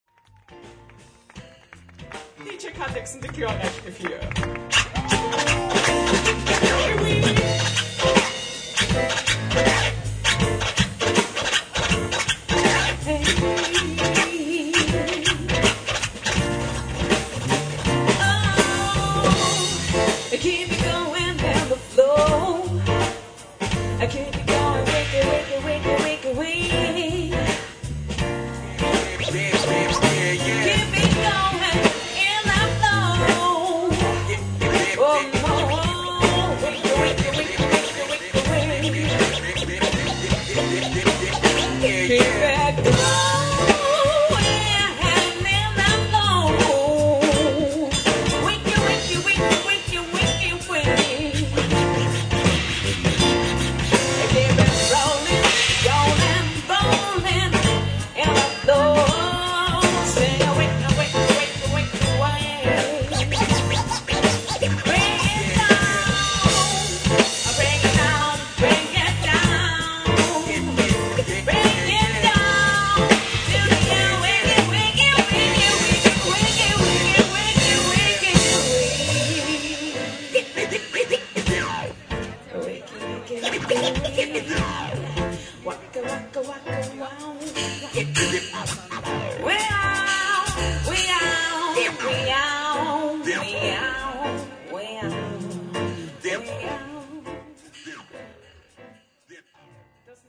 mp3 von der beeindruckenden Improvisation zwischen